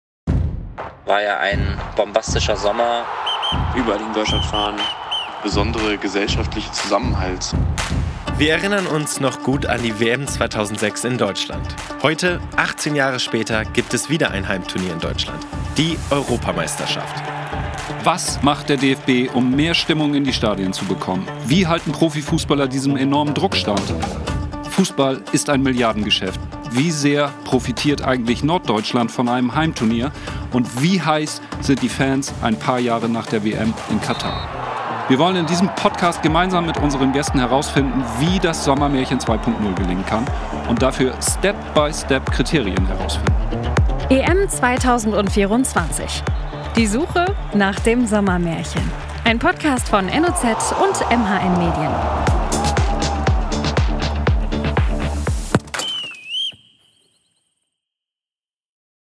Trailer zum Podcast